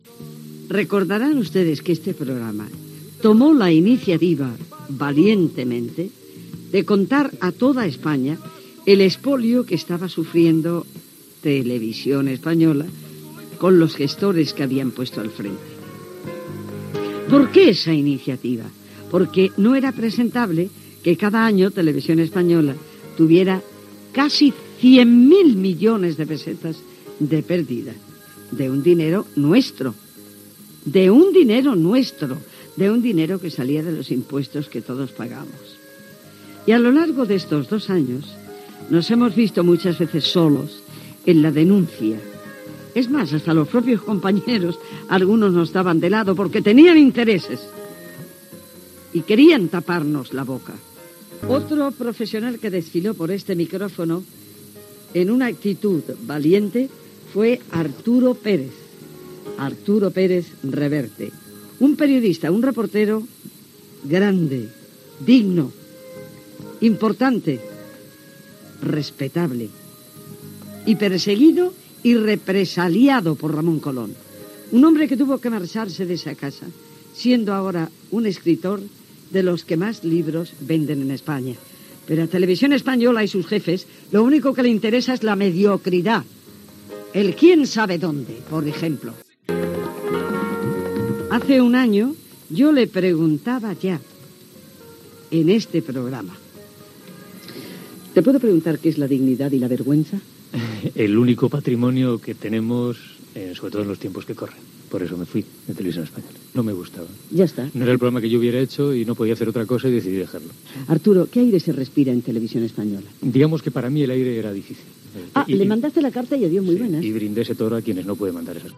Crítica a la gestió econòmica dels gestors de RTVE, fragment d'una entrevista feta a l'escriptor Arturo Pérez Reverte, l'any 1995, qui havia deixat de treballar a l'ens públic espanyol l'any 1994
Info-entreteniment